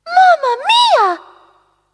One of Princess Peach's voice clips in Mario Party 5